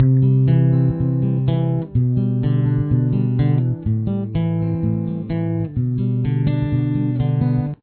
Intro/Verse Riff